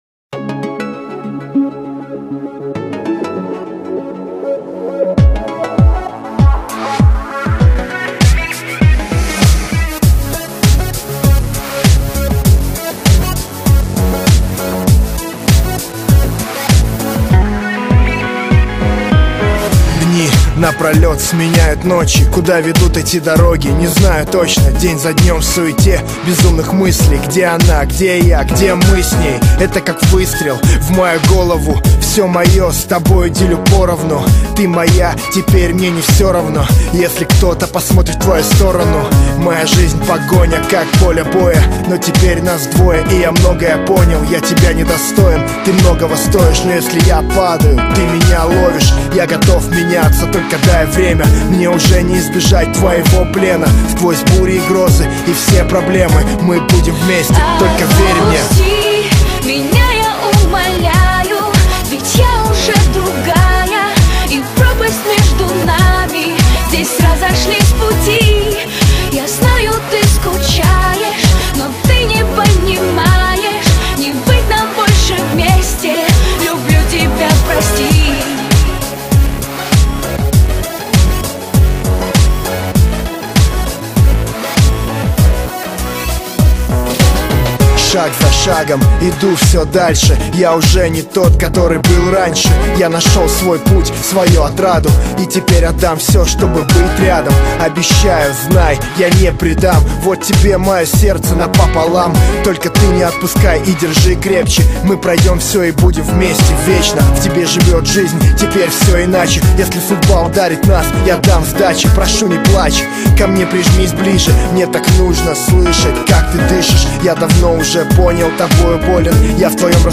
Категория: Русский реп, хип-хоп